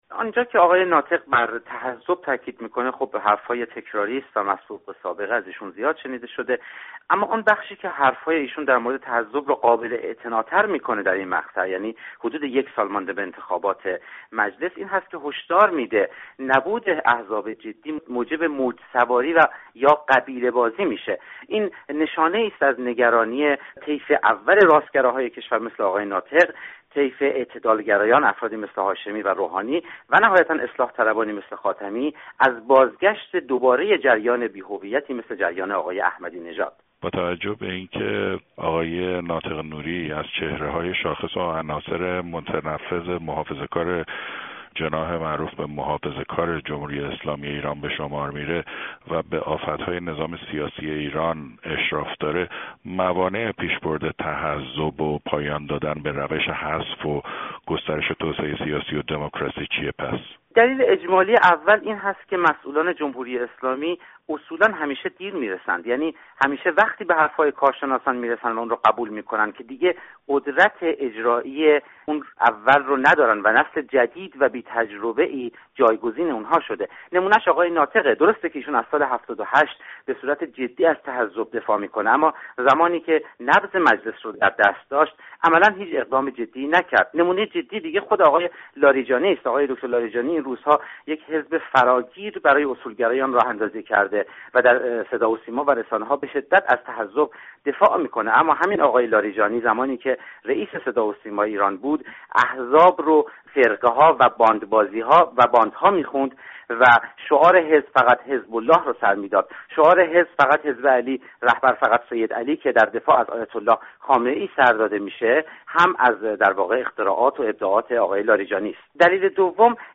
از رادیو فردا